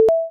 bling1.ogg